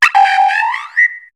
Fichier:Cri 0619 HOME.ogg — Poképédia
Cri de Kungfouine dans Pokémon HOME.